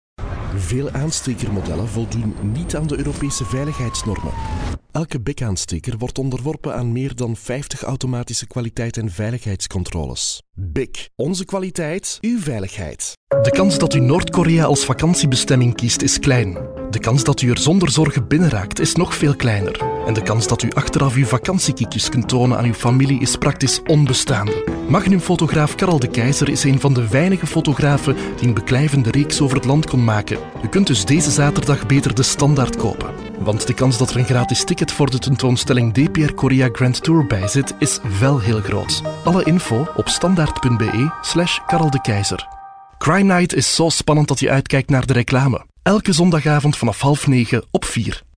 Deep, Young, Natural, Friendly, Warm
Commercial